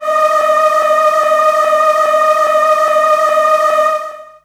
55be-syn16-d#4.wav